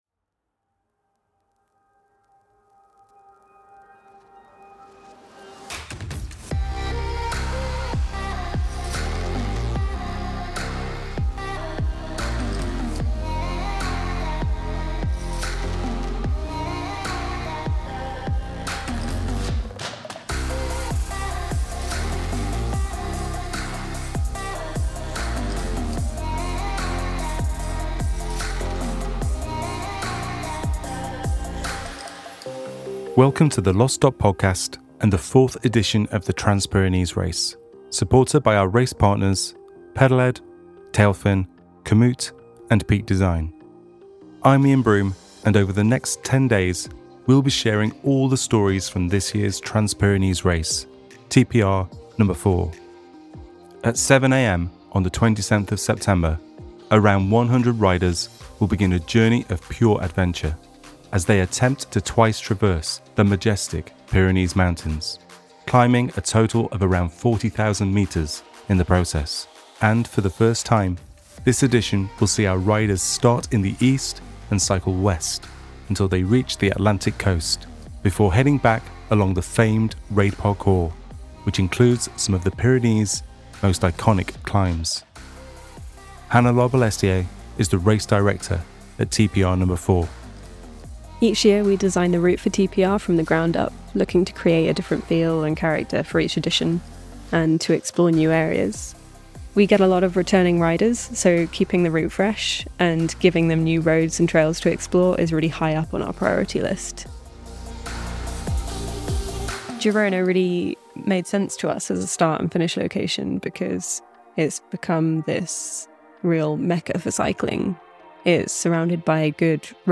Tune in to some insightful interviews with key riders, ones to watch, and Lost Dot vets recorded in the weeks leading up to when it all kicks off: this Friday at 07:00CEST 27th September, 2024.